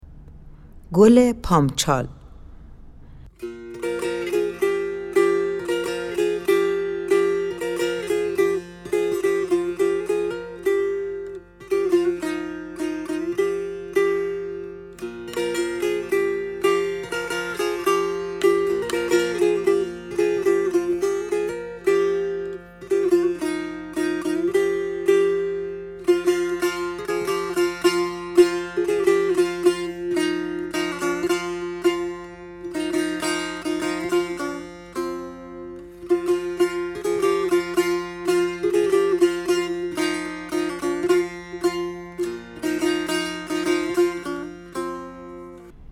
آموزش آهنگ گل پامچال برای سه تار
آهنگ گل پامچال در واقع ترانه ای گیلکی در آواز دشتی است که سیدجعفر مهرداد در سال 1330 آن را سروده است.
خرید بسته آموزشی سه تار بخش آواز دشتی